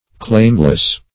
Claimless \Claim"less\, a.